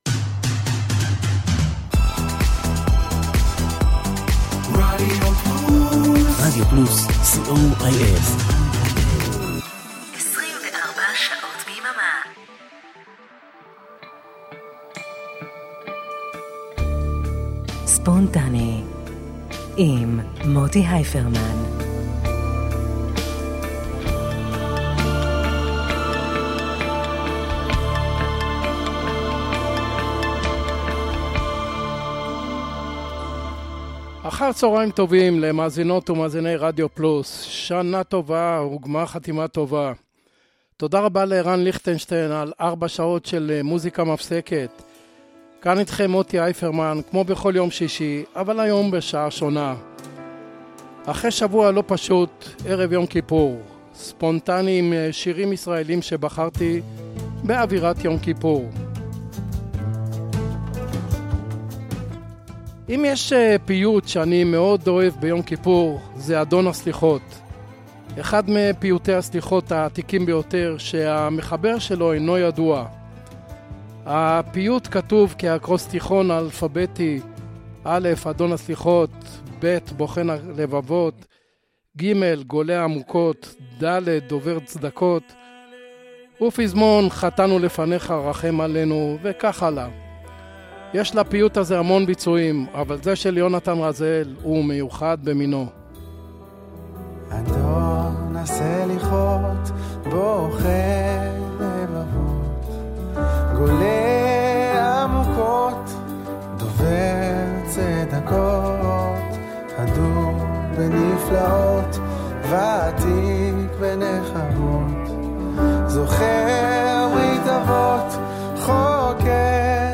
classic rock israeli rock pop rock